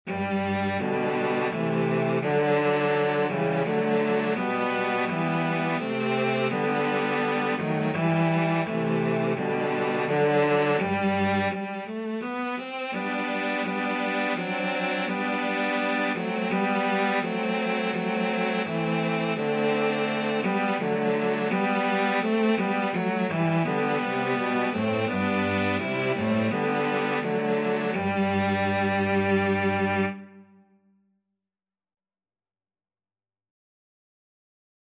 Cello 1Cello 2Cello 3
3/4 (View more 3/4 Music)
Cello Trio  (View more Easy Cello Trio Music)